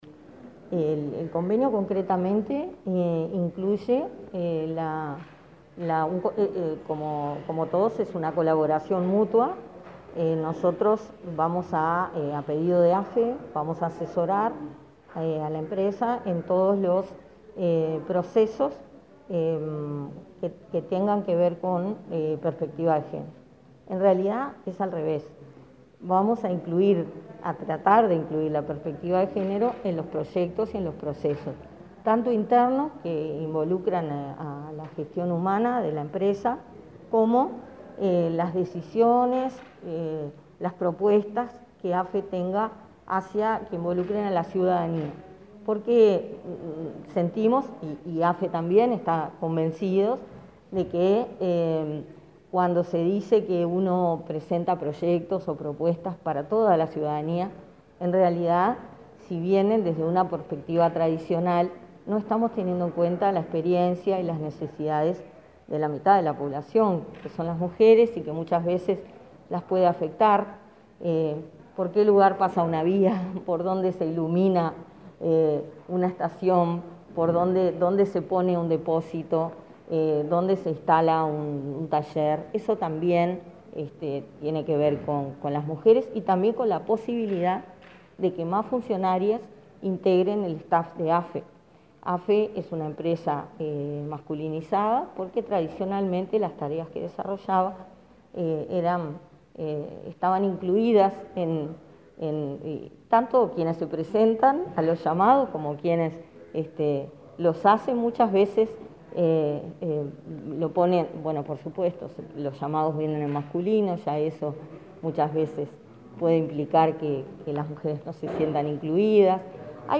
Entrevista a la directora de Inmujeres